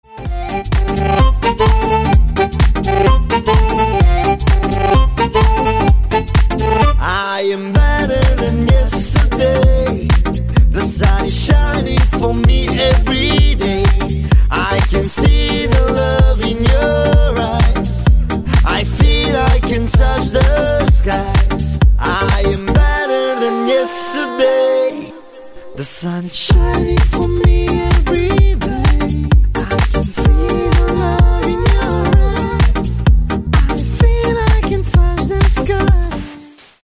мужской вокал
женский голос
dance
электронная музыка
дуэт
house
Дуэт румынских исполнителей...